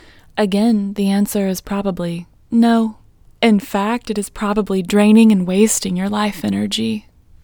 OUT Technique Female English 25